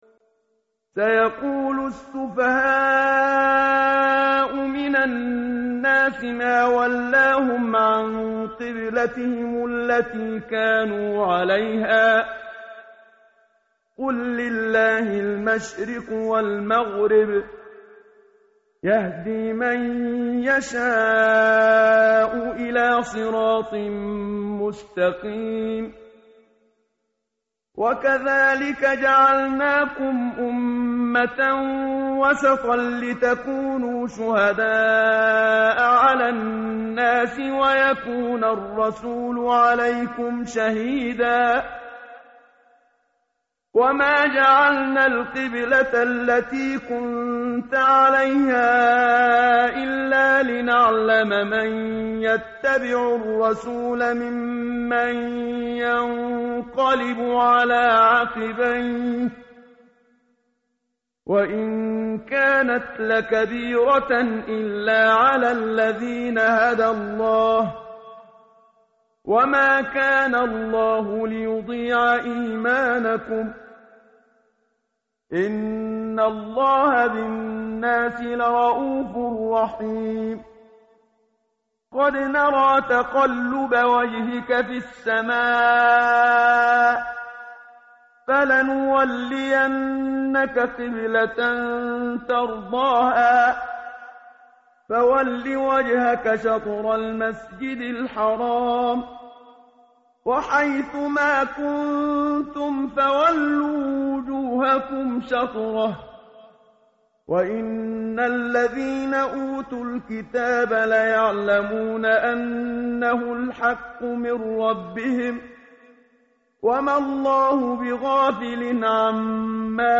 متن قرآن همراه باتلاوت قرآن و ترجمه
tartil_menshavi_page_022.mp3